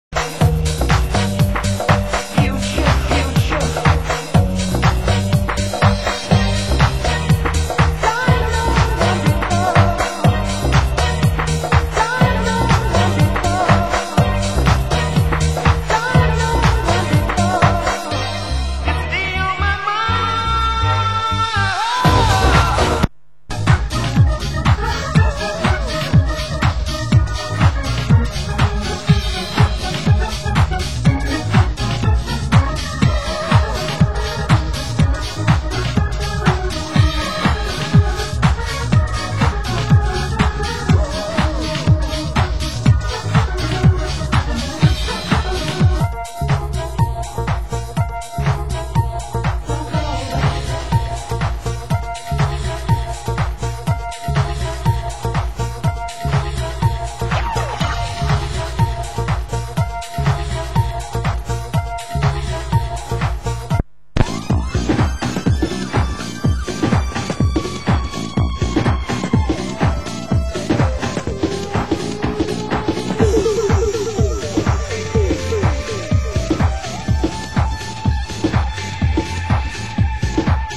Format: Vinyl 12 Inch
Genre: Deep House